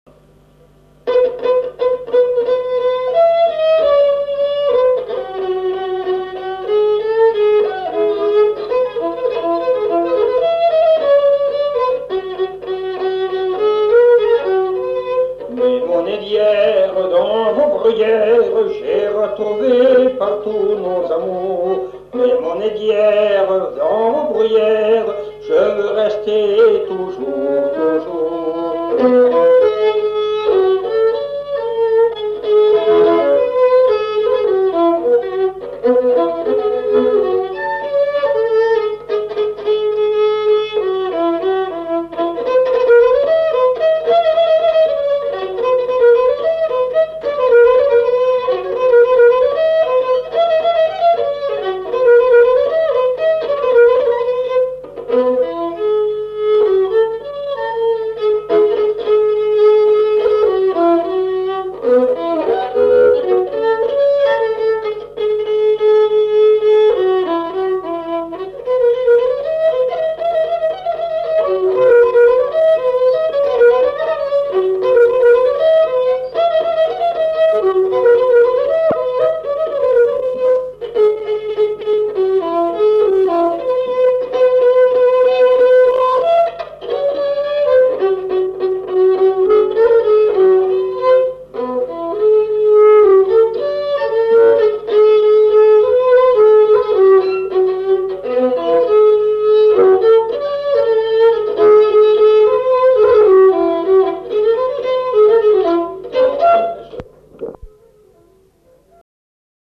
Aire culturelle : Auvergne
Lieu : Villeneuve-sur-Lot
Genre : chanson-musique
Type de voix : voix d'homme
Production du son : chanté
Instrument de musique : violon
Danse : valse